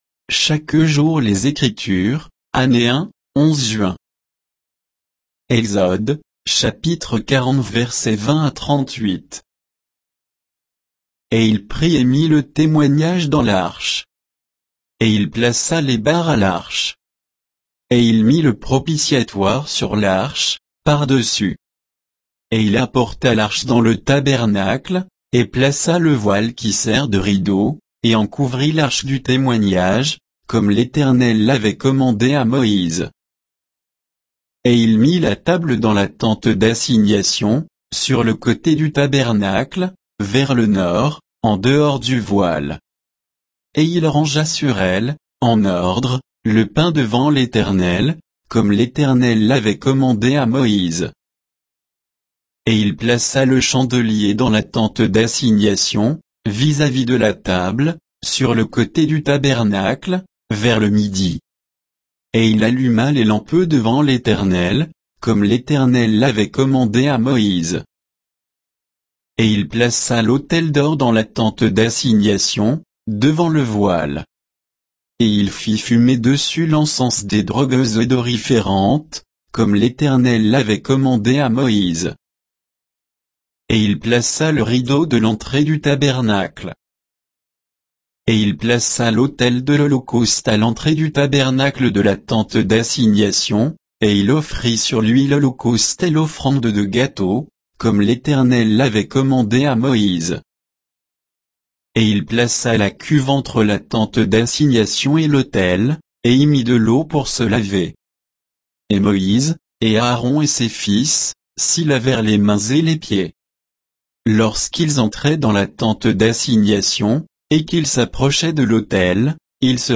Méditation quoditienne de Chaque jour les Écritures sur Exode 40, 20 à 38